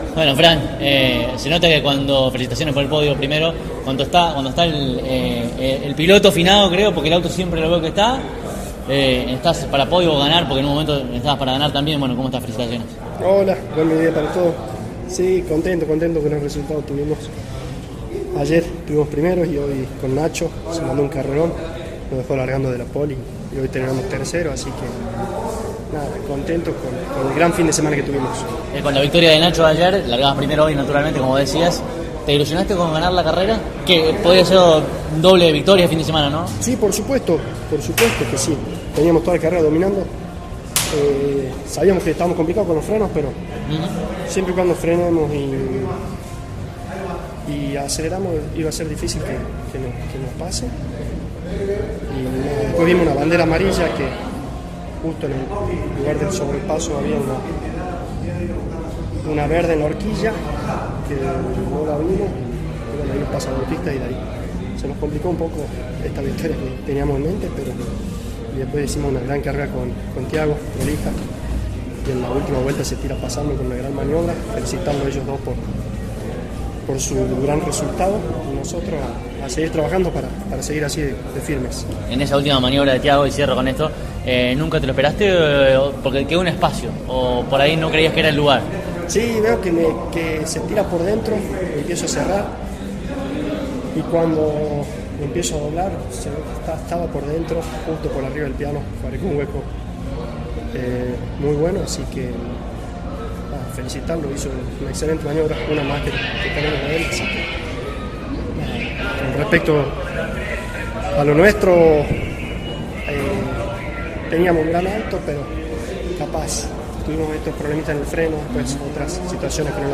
Estas son todas las entrevistas: